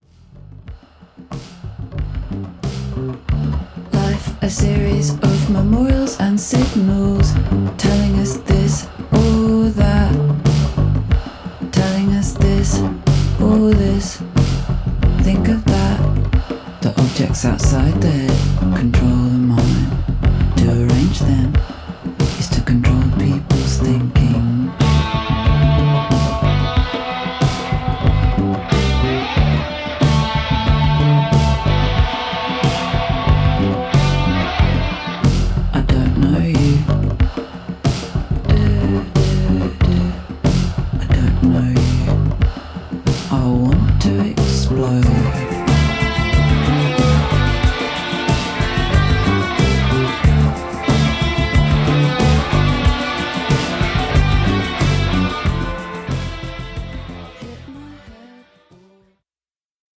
クールでスリリングなアルバムです。